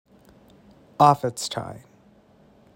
Offetstine (/ˈɔf.ɛtˌstaɪn/, GenAm: